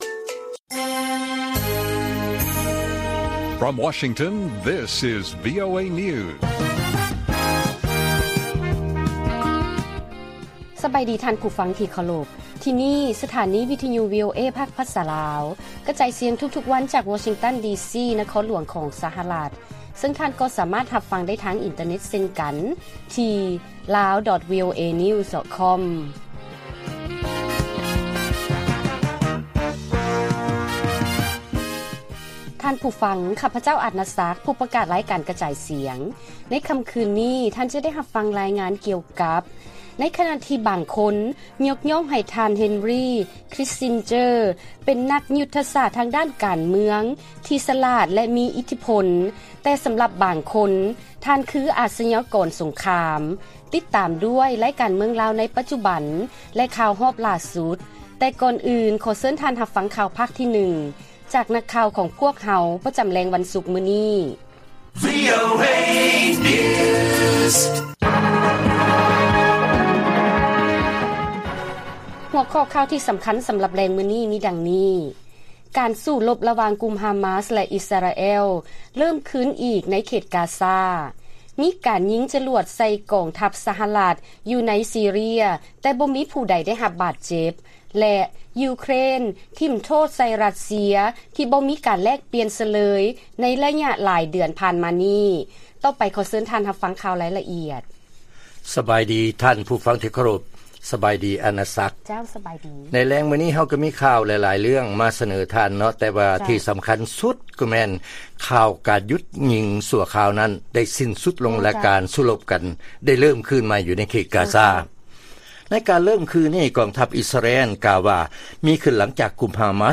ລາຍການກະຈາຍສຽງຂອງວີໂອເອ ລາວ: ການສູ້ລົບລະຫວ່າງກຸ່ມຮາມາສ ແລະອິສຣາແອລ ເລີ້ມຄືນອີກ ໃນເຂດກາຊາ